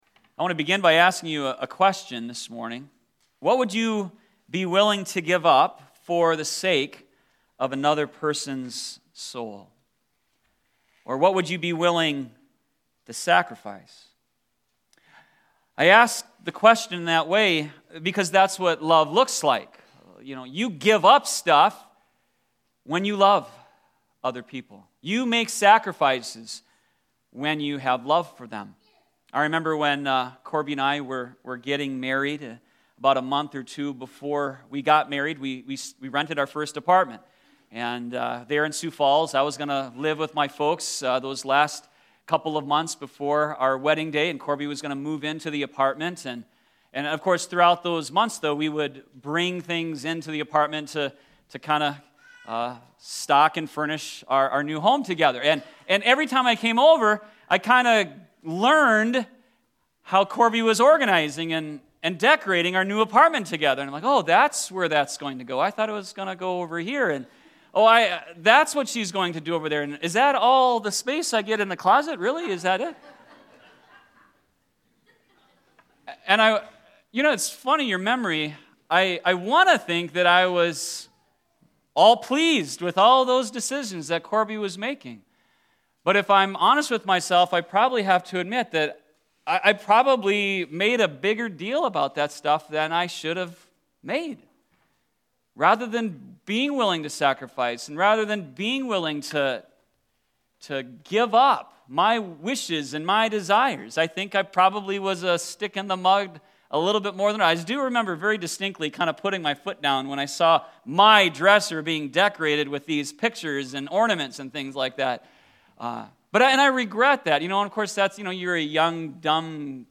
Sermon020517_2.mp3